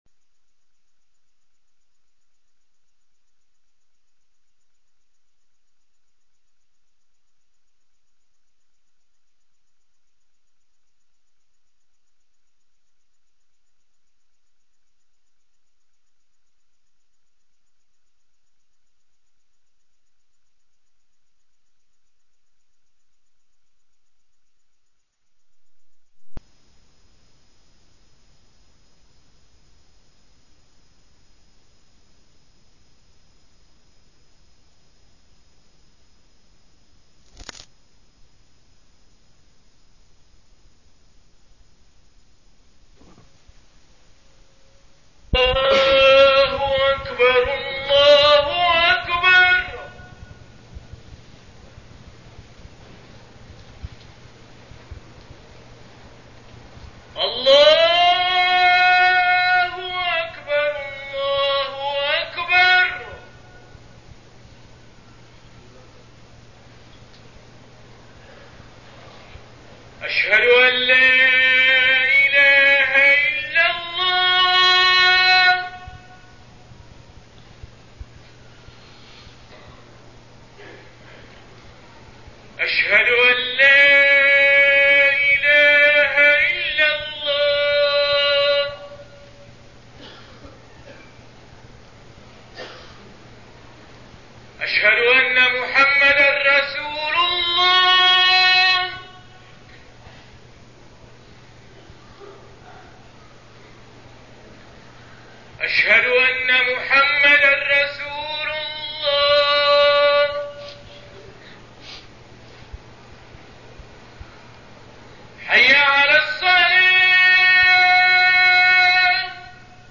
تاريخ النشر ٣ جمادى الآخرة ١٤٠٨ هـ المكان: المسجد الحرام الشيخ: محمد بن عبد الله السبيل محمد بن عبد الله السبيل الإيمان و تصديقه بالعمل الصالح